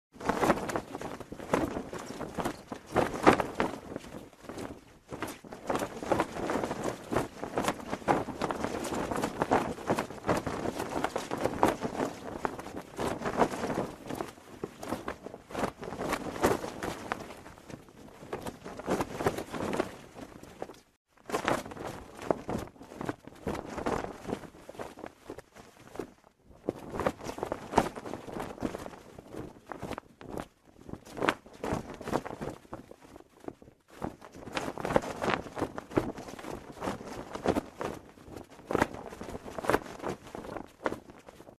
Звуки флага
Развевающийся флаг для установки